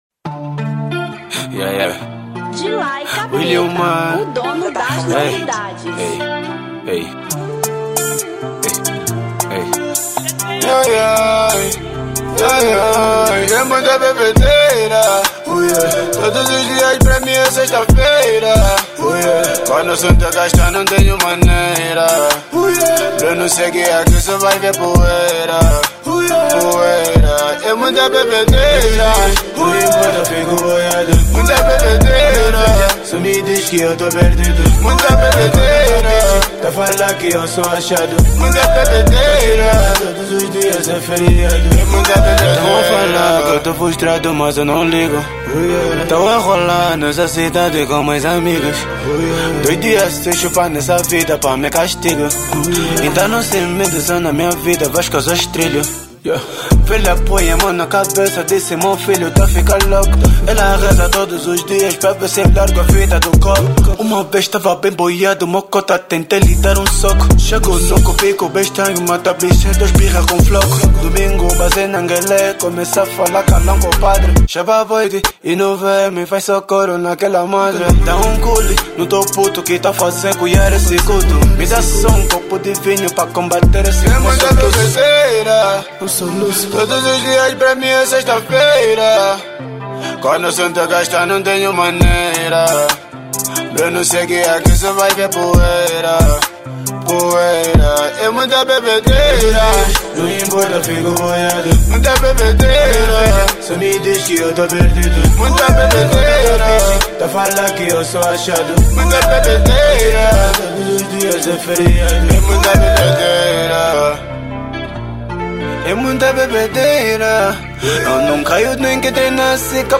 Drill 2022